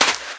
STEPS Sand, Run 12.wav